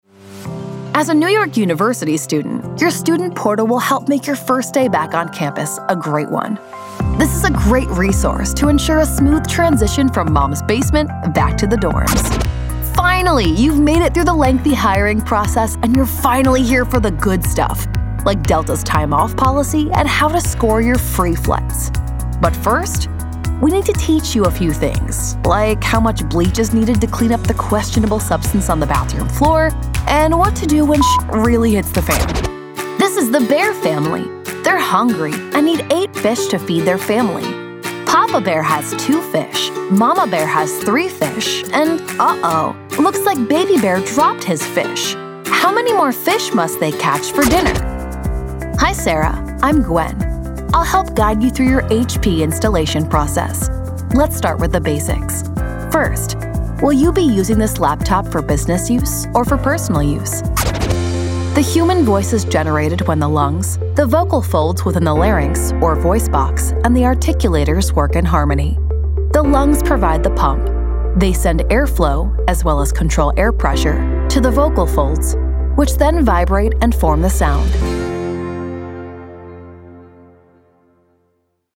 Warm, Friendly, Conversational.
eLearning